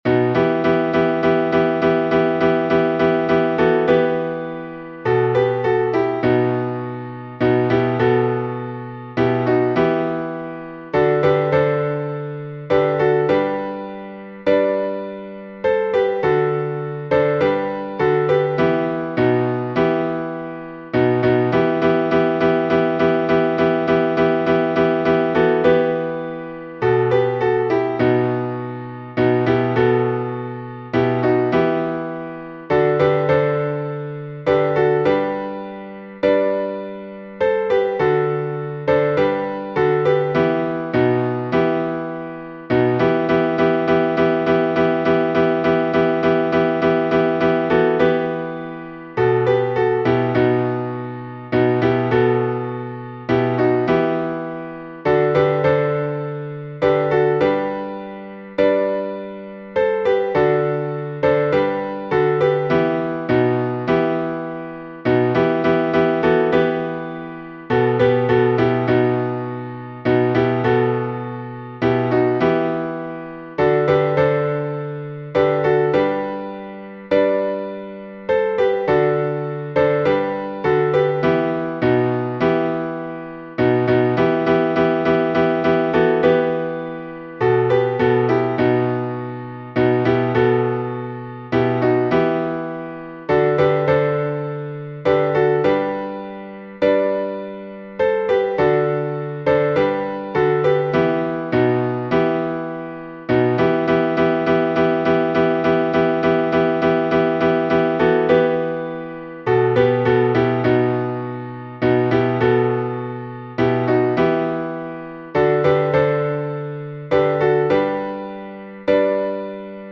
blazhen_muzh_pochaevskoe.mp3